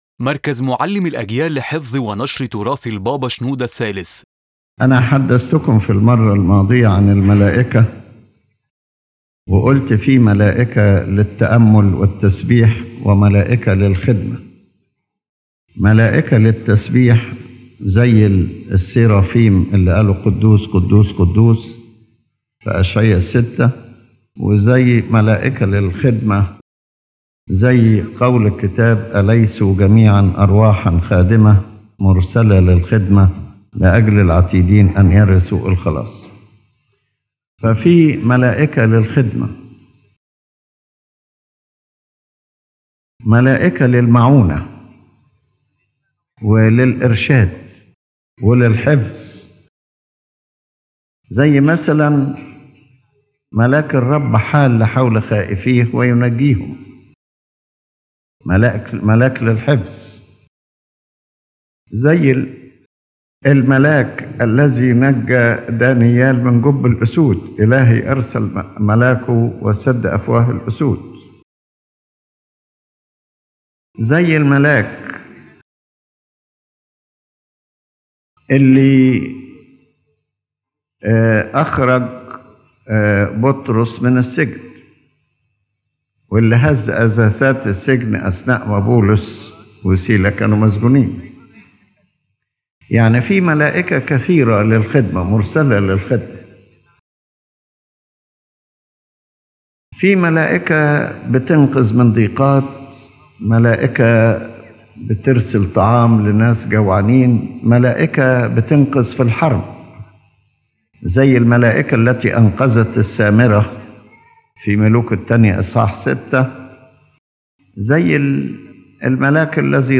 His Holiness Pope Shenouda III in this lecture addresses the nature of angels, their multiple attributes and varied functions: angels of praise and angels of service, and explains their continuous relationship with man and their role in salvation and theological history.